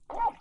picto son animaux marins
Cri_jeune_phoque_commun_Underwater_clean_plus_01.wav